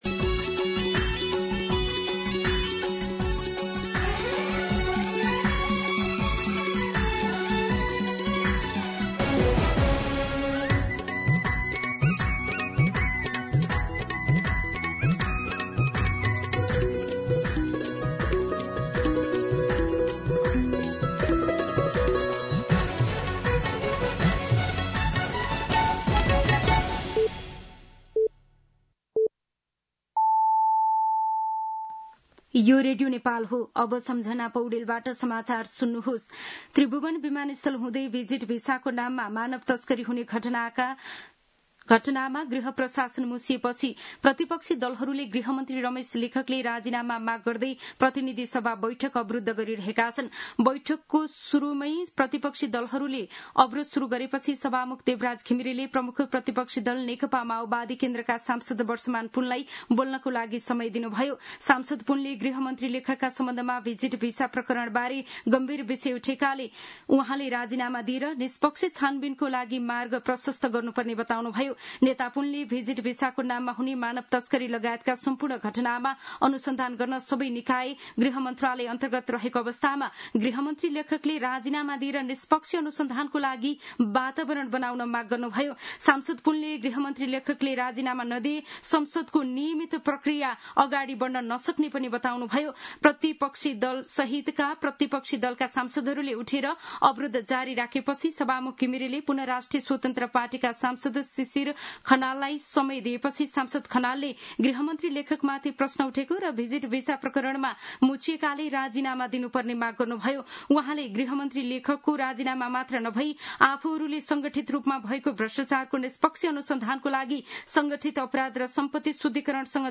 दिउँसो १ बजेको नेपाली समाचार : १३ जेठ , २०८२